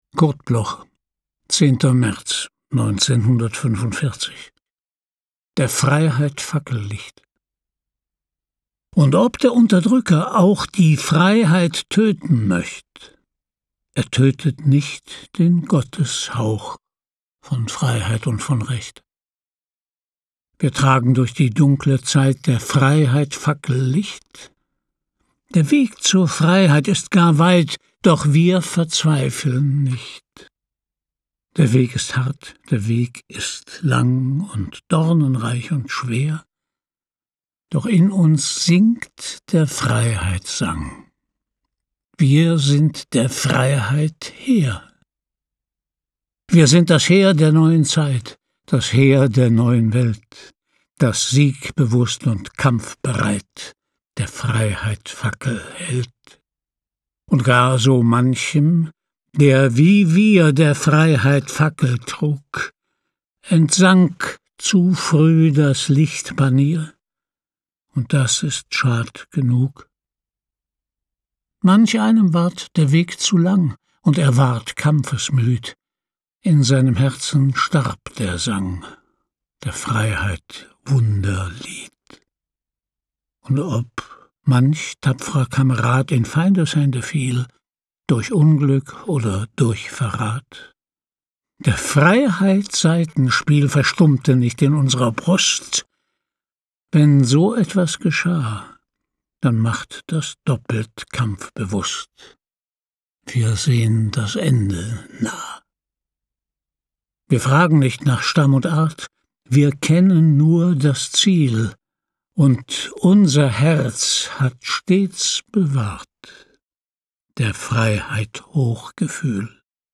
Recording: studio_wort, Berlin · Editing: Kristen & Schmidt, Wiesbaden
Christian Brückner (* 1943) is een Duits acteur en voice-overartiest.